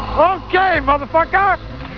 Al Pacino gets a little frusterated at the ship yard and yells OK Motherfuc*@#